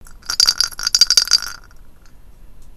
あご君土鈴 その4の音(mp3音声ファイル,4秒,70kバイト)